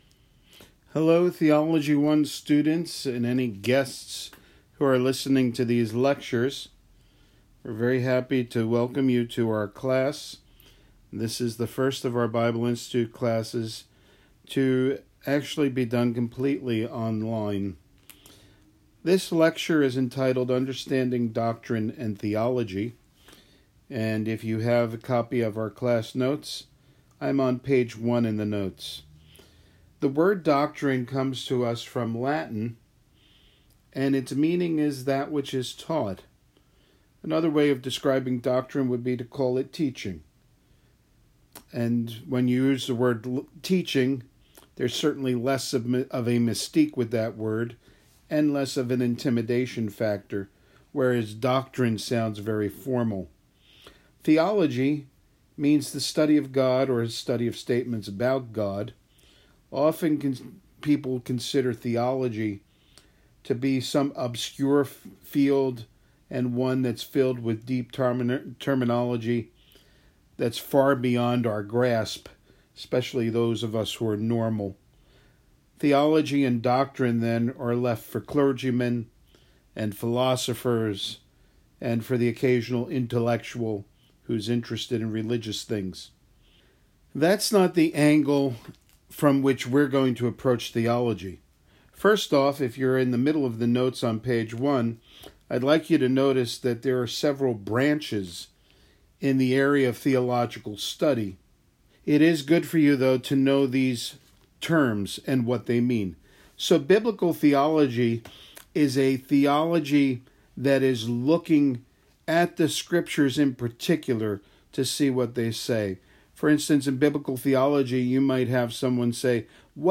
DOC_102_Theology_1_-_Lecture_1_Understanding_Doctrine_and_Theology.mp3